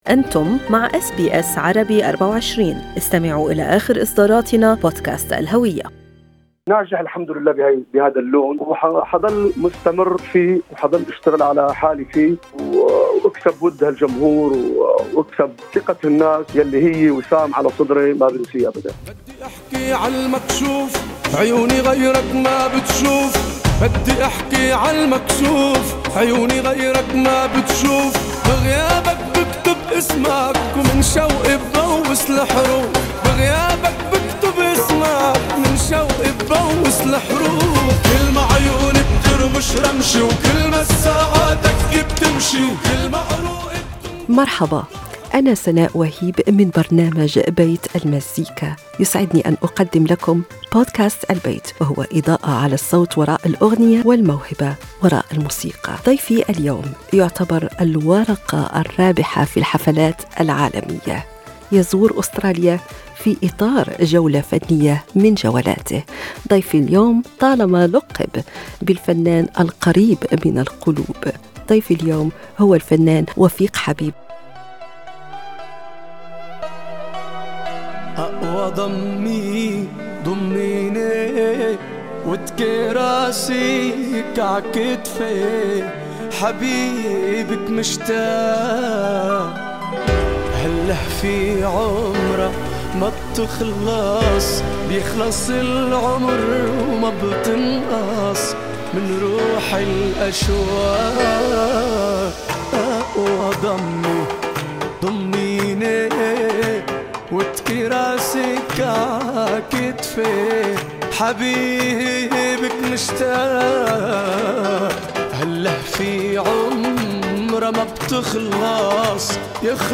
وفي إطار جولته الفنية له في أستراليا، أجرى برنامج بيت المزيكا لقاء مع الفنان وفيق حبيب المعروف أيضا لدى محبيه ومتابعيه بأبو دريد.